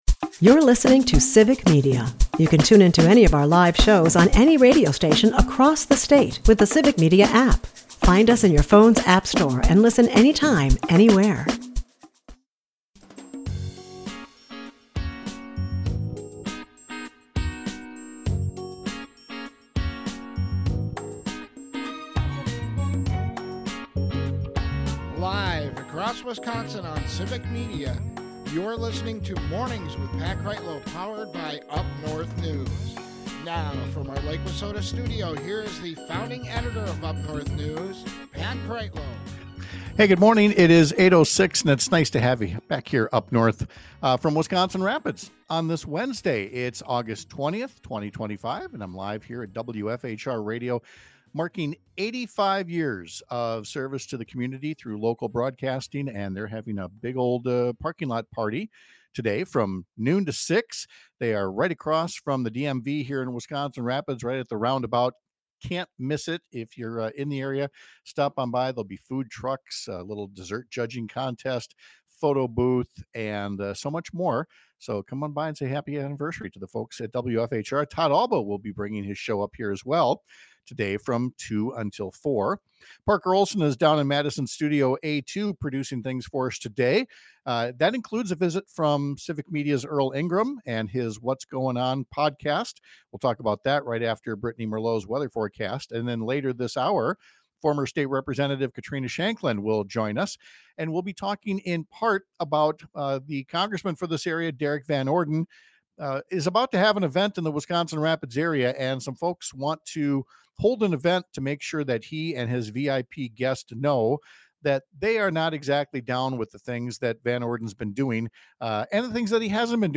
Guests: Katrina Shankland